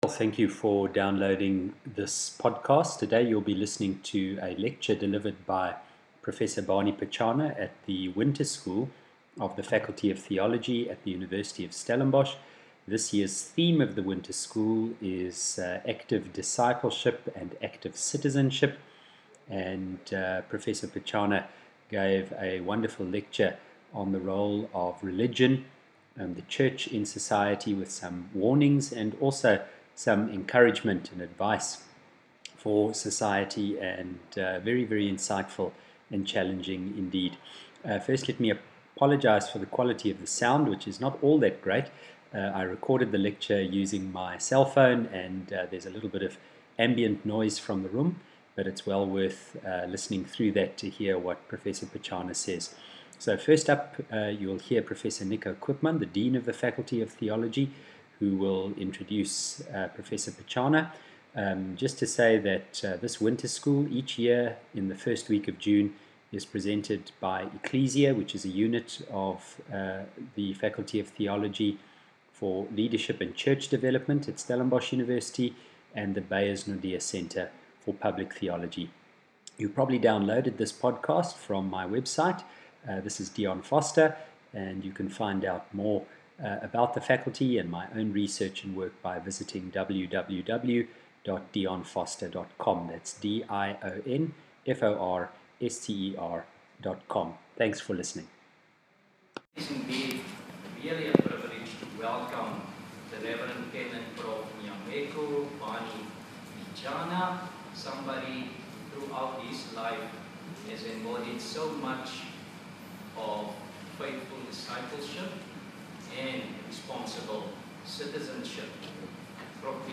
You can download Prof Barney Pityana's opening Keynote on Discipleship Active Citizenship which was delivered on 2 June 2015 at the Winter School of the Faculty of Theology at Stellenbosch University from this link [mp3 file, 50MB]
I apologize for the poor sound quality of the recording. I recorded it using my cellphone and so there is some ambient and room noise in the recording.
Barney Pityana 2 June 2015 Winter School.mp3